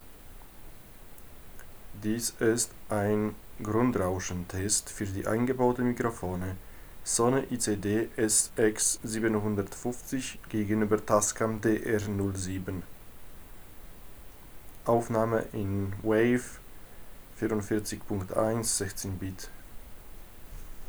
Die Dateien sind als WAV aufgenommen worden und fĂźrs Web in 192kbps MP3 mit LAME umgewandelt.